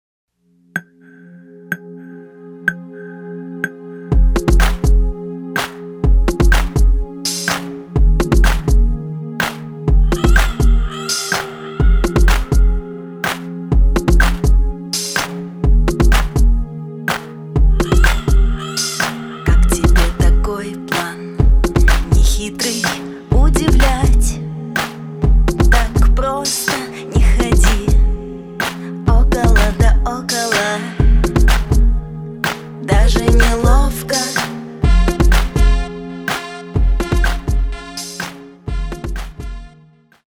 И еще арпеджиатор и биткрашер... с битностью 2 :D